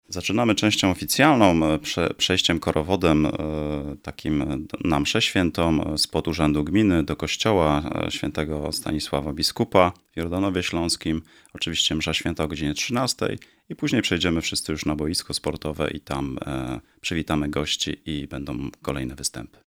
– Startujemy przemarszem korowodu dożynkowego spod Urzędu Gminy do kościoła przy akompaniamencie orkiestry dętej z Dzierżoniowa – mówi Paweł Filipczak, wójt gminy Jordanów Śląski.